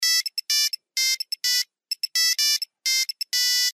Короткие рингтоны
Рингтоны на смс и уведомления
Электронные